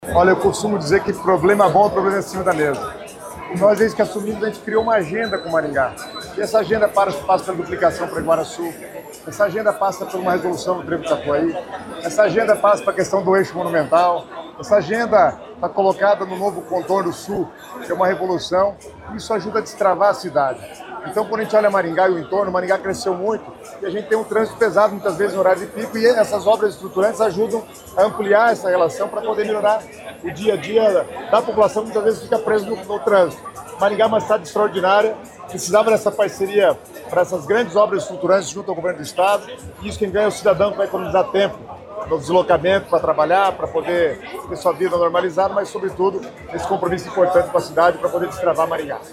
Sonora do secretário das Cidades, Guto Silva, sobre a inauguração do novo Trevo do Catuaí, em Maringá